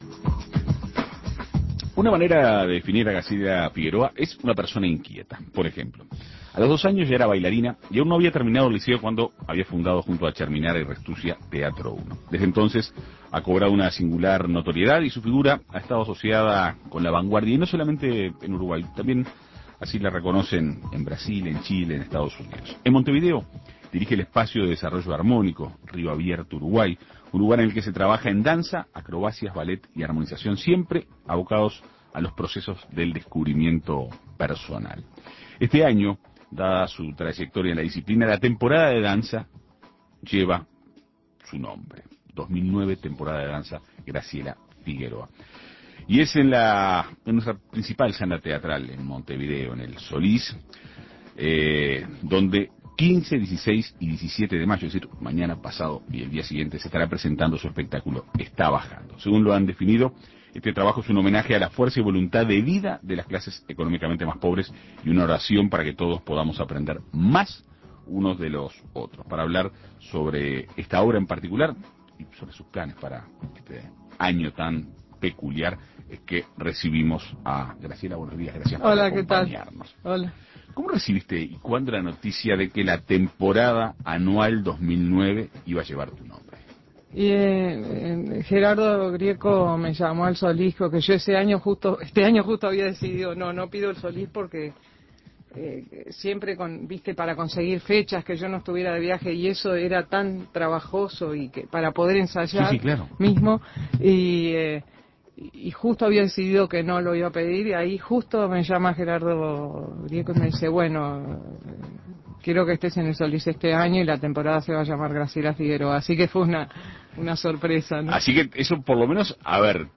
Es en la sala principal de la institución donde el 15, 16 y 17 de mayo presentará su espectáculo "Está bajando". En Perspectiva Segunda Mañana dialogó con la artista para conocer los detalles de la obra.